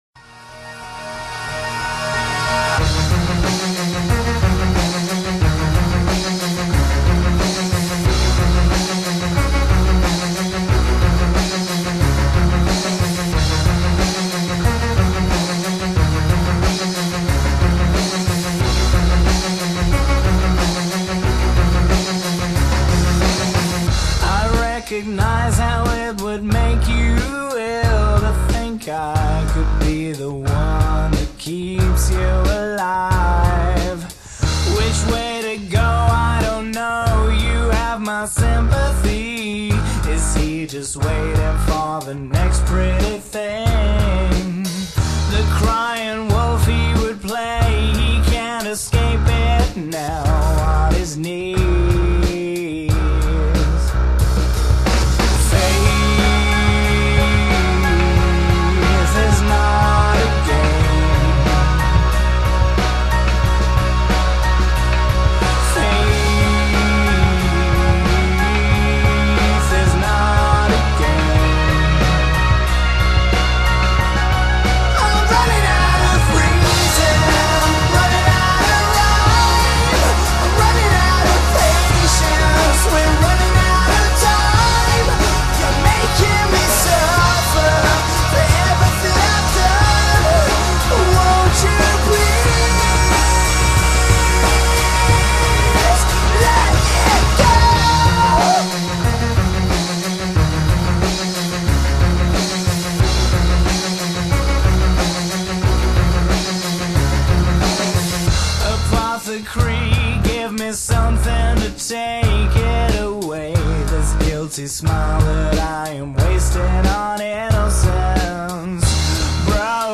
Heavy Rock
Heavy metal
Rock & Roll